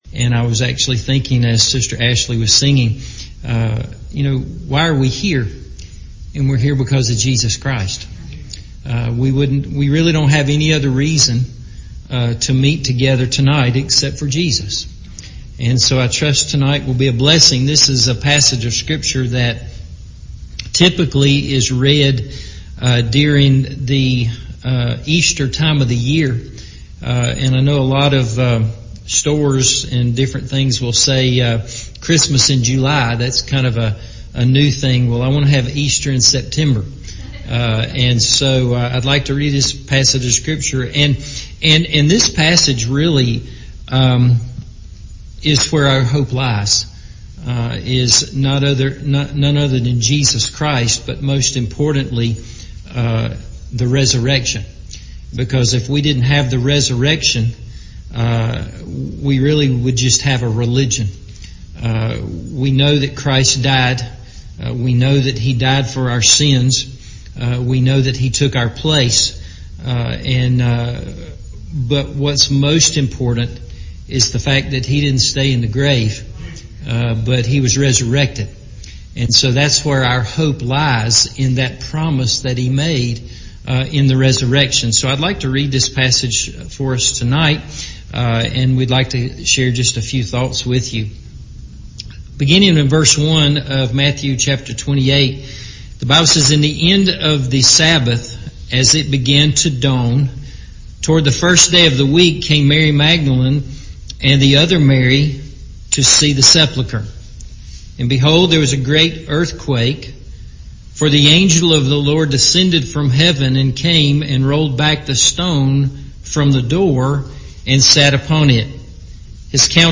Hope Rose with the Dawn – Evening Service